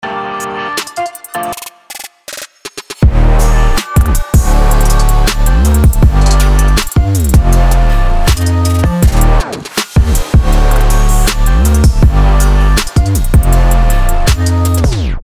Drum Samples, Loops and Melodies will help you kick-start your production and get the best beat right from the start.|
7 KILLER 808s – all you need
7 PUNCHY CLAPS – all you need
8 LO-FI & HIP HOP KICKS
11 hard hitting SNARES
15 PERCUSSION – shakers, rims & claves
24 DRUM LOOPS in 80bpm, 110bpm, 140bpm & 160bpm